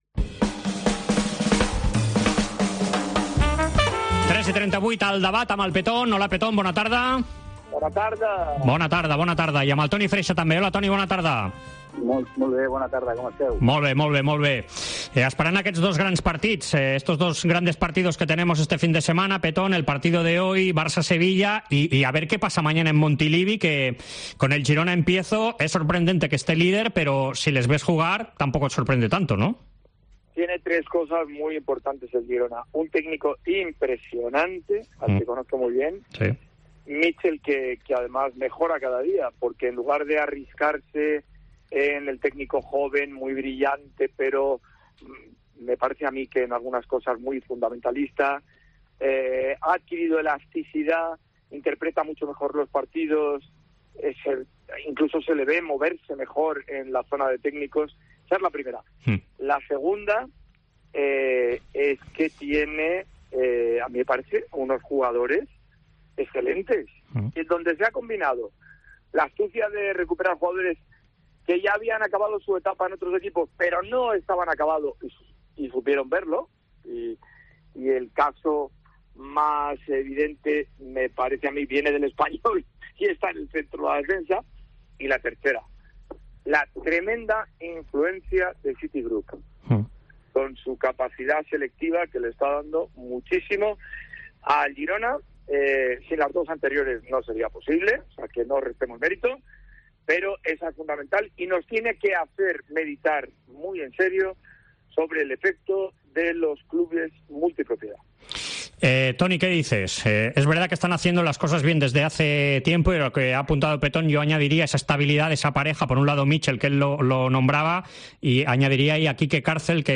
AUDIO: Els dos col·laboradors de la Cadena COPE repassen l'actualitat esportiva d'aquesta setmana.